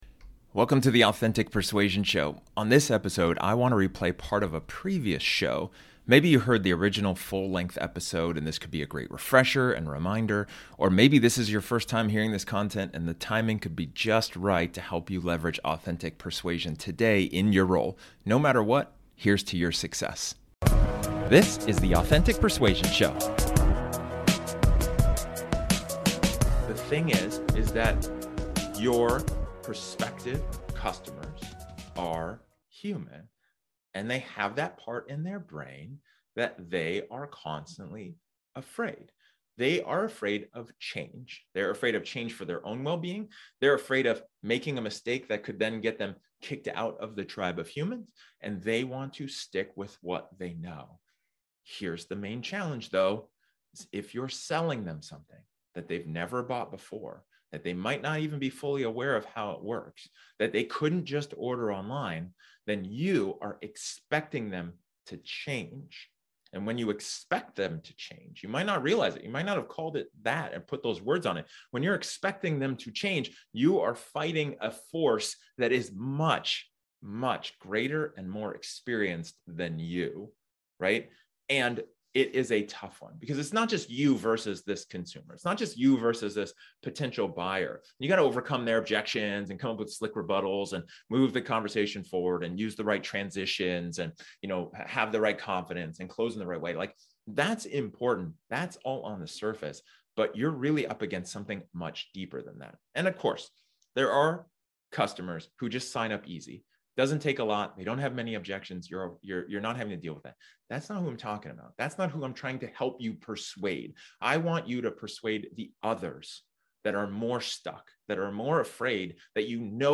This episode is an excerpt from one of my training sessions where I talk about the question: "What are you afraid of?"